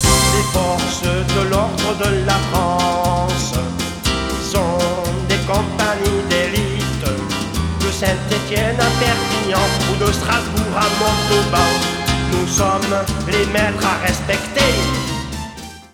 Chanson enregistrée en 1993 au Studio ARION à NICE